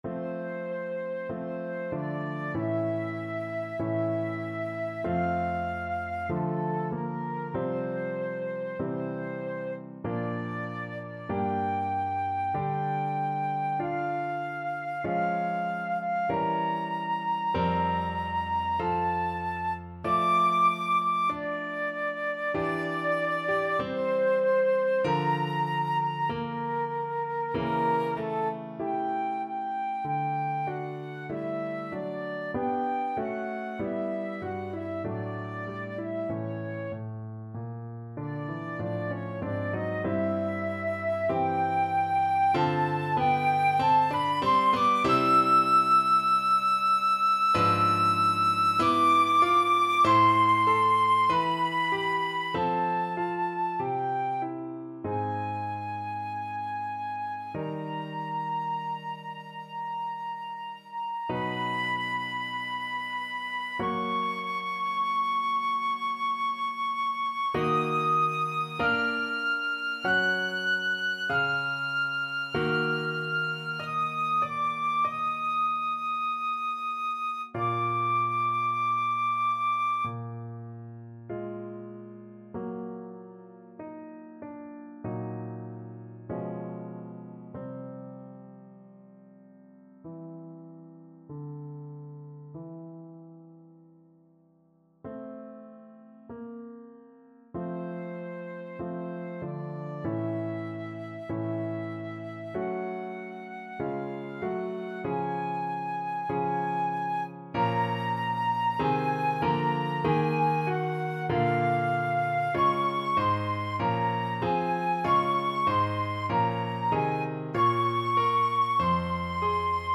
Flute
2/2 (View more 2/2 Music)
~ = 96 Alla breve. Weihevoll.
F major (Sounding Pitch) (View more F major Music for Flute )
Classical (View more Classical Flute Music)